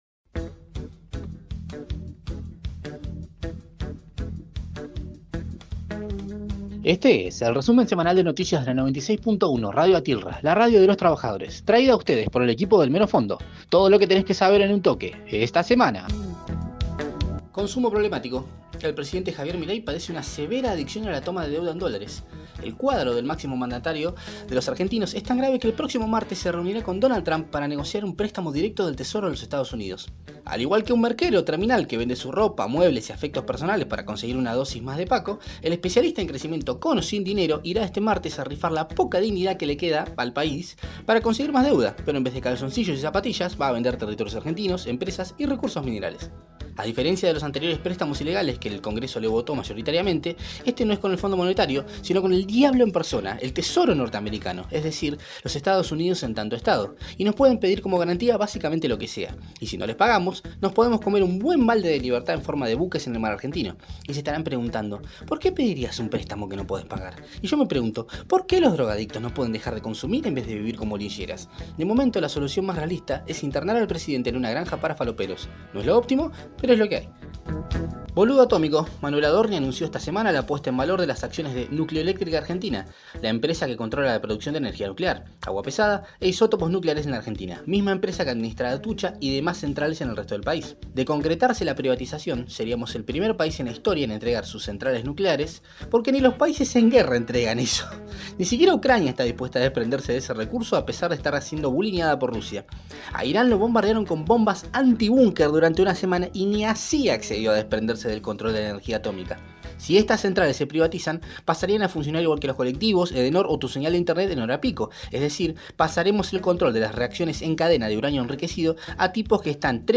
Flash Informativo
con mucho humor y sarcasmo